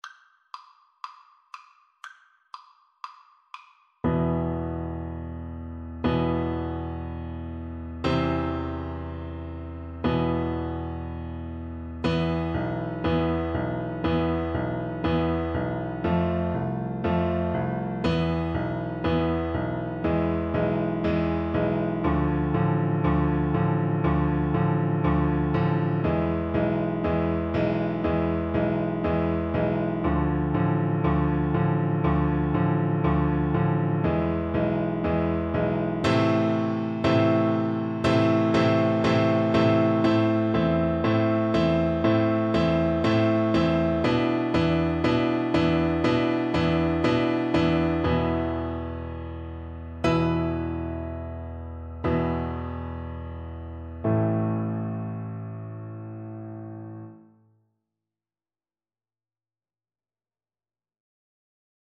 Traditional Trad. Hava Nagila Orchestral Percussion version
Xylophone
A minor (Sounding Pitch) (View more A minor Music for Percussion )
4/4 (View more 4/4 Music)
Vivace (View more music marked Vivace)
Traditional (View more Traditional Percussion Music)